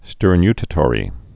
(stûr-nytə-tôrē, -n-)